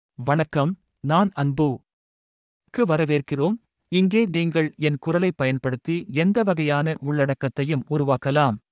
MaleTamil (Singapore)
AnbuMale Tamil AI voice
Anbu is a male AI voice for Tamil (Singapore).
Voice sample
Listen to Anbu's male Tamil voice.
Anbu delivers clear pronunciation with authentic Singapore Tamil intonation, making your content sound professionally produced.